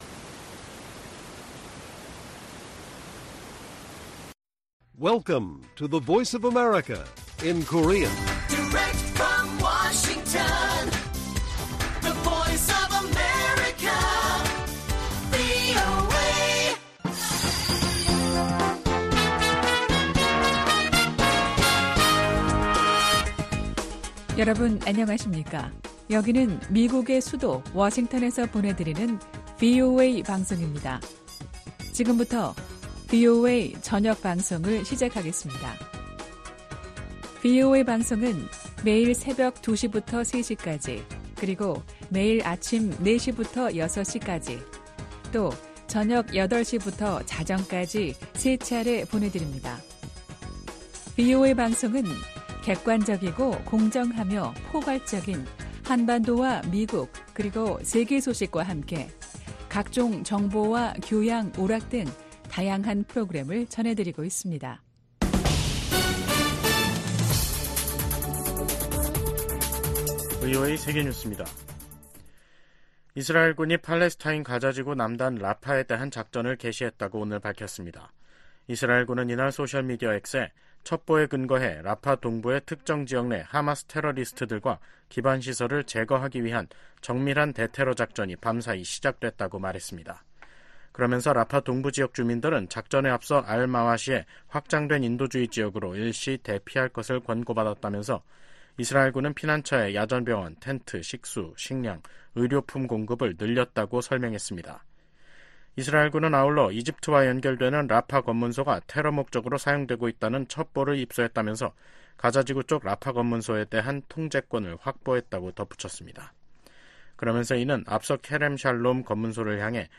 VOA 한국어 간판 뉴스 프로그램 '뉴스 투데이', 2024년 5월 7일 1부 방송입니다. 백악관이 유엔이 정한 연간 한도를 초과한 대북 정제유 공급과 관련해 제재를 이행하지 않고 있는 나라가 있다고 비판했습니다. 국방부가 6일 북한의 로켓 엔진시험 정황과 관련해 한국과 일본에 대한 굳건한 방위 공약을 재확인했습니다. 미국과 한국 일본, 유럽국가들이 러시아의 유엔 안보리 거부권 남용을 비판했습니다.